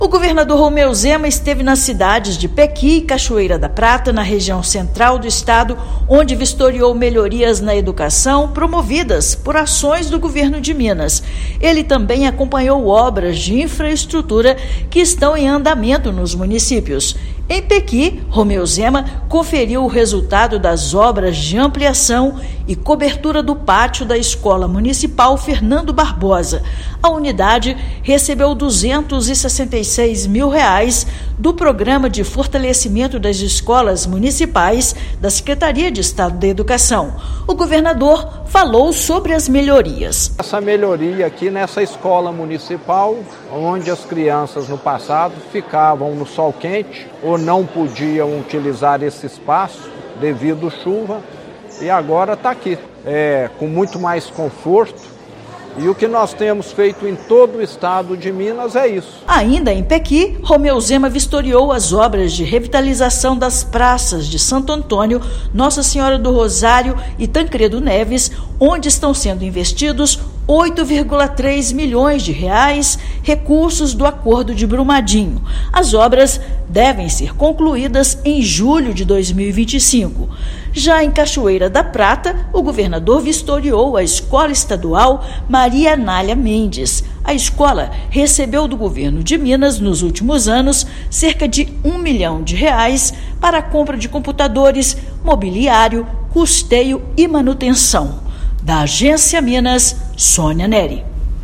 Além de verificar a estrutura de unidades escolares dos municípios, chefe do Executivo também acompanhou andamento de obras de infraestrutura. Ouça matéria de rádio.